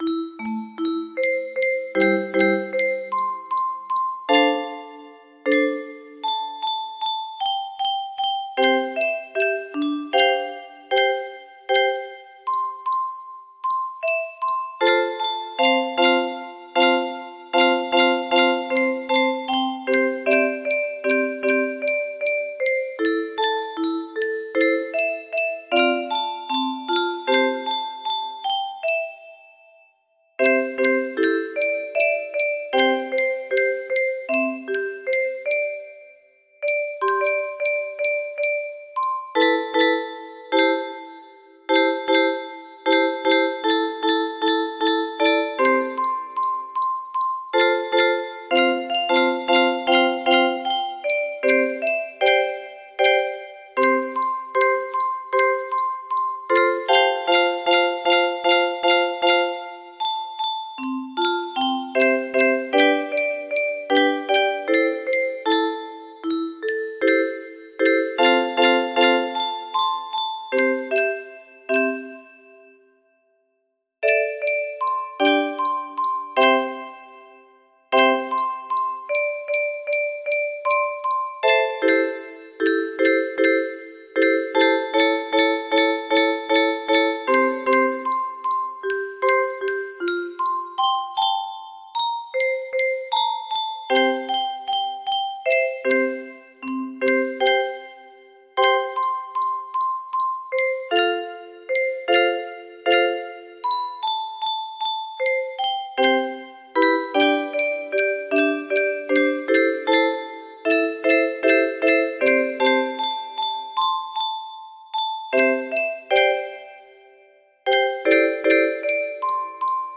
Unknown Artist - Untitled music box melody
Full range 60
Imported from MIDI from imported midi file (4).mid